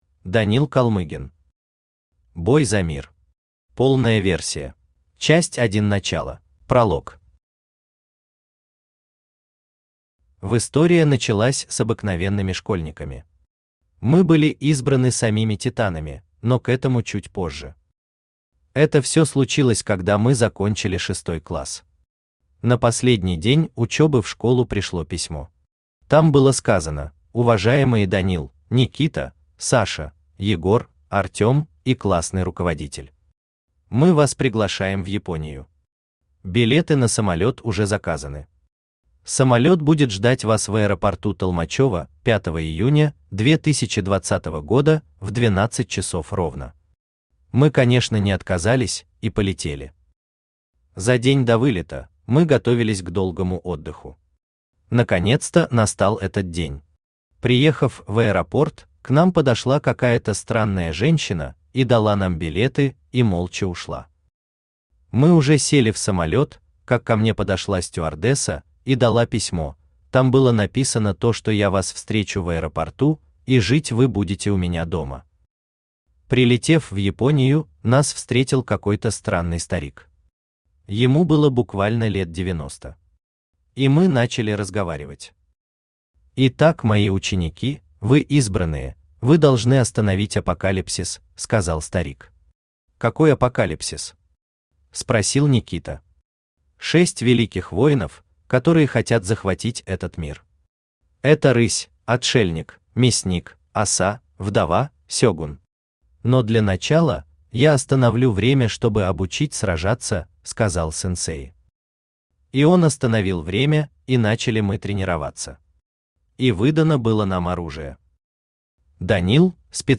Аудиокнига Бой за мир. Полная версия | Библиотека аудиокниг
Полная версия Автор Данил Алексеевич Колмыгин Читает аудиокнигу Авточтец ЛитРес.